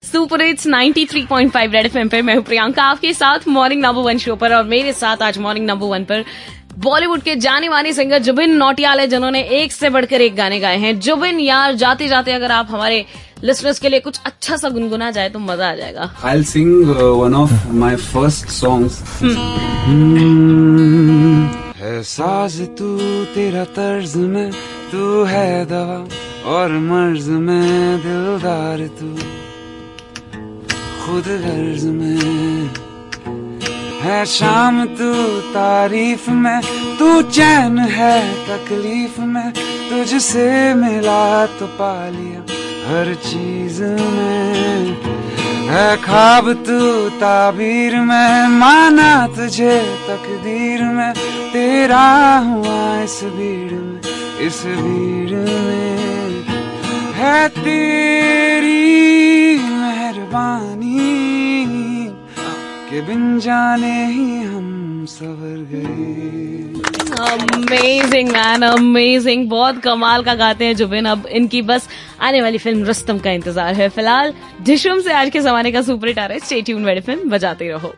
talking to jubin nautiyal